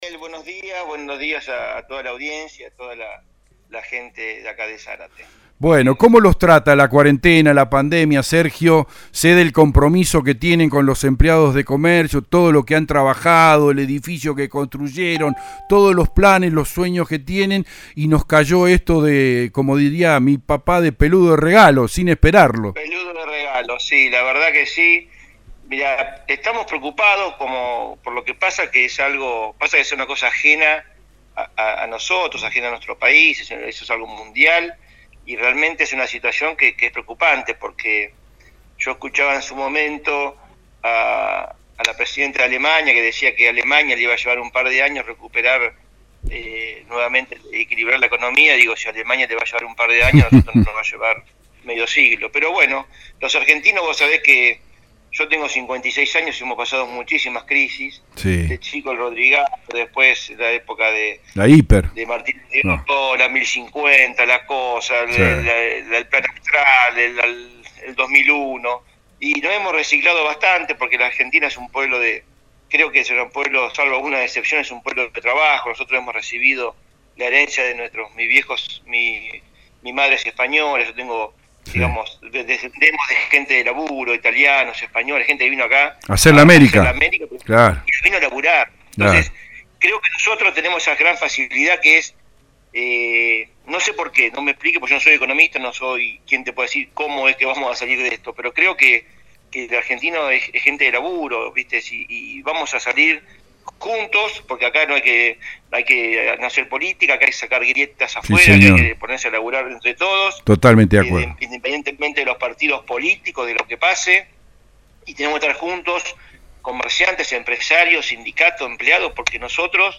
en el programa Con Zeta de radio EL DEBATE